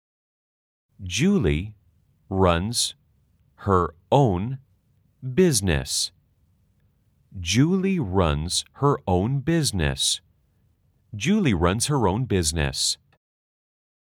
문장은 3번 반복되고 속도가 점점 빨라집니다.
/ 쥬울리 / 뤄언즈 허뤄언 / 비이즈니이스 /
her own은 한 단어처럼 붙여서 발음해주세요.